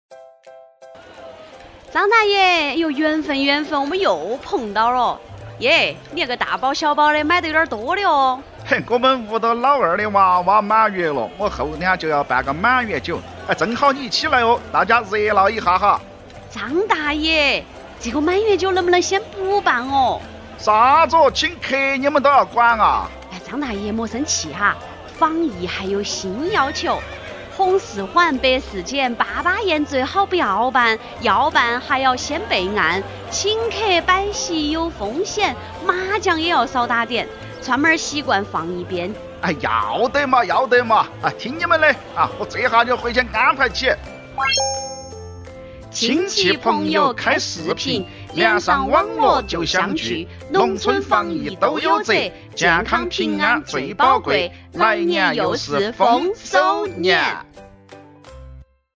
川话广播剧 教你农村防疫怎么做
四川农村防疫广播剧（下）
剧中讲述的是生活、居住在四川农村的“张大爷”，与他们村社的网格员“小郭”，围绕农村疫情防控要求“碰撞”出的故事。剧中采用亲切通俗的四川话，设置了生动鲜活的两个故事情节。